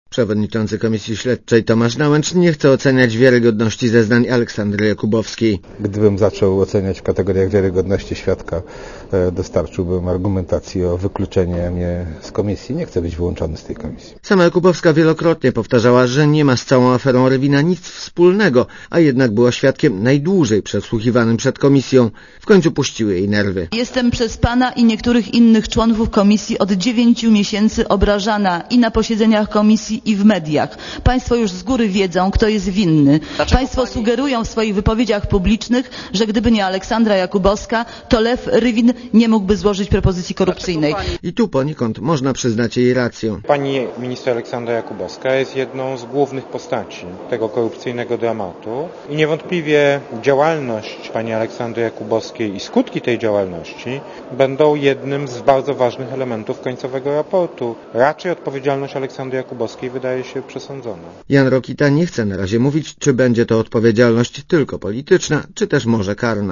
Komentarz audio (260Kb)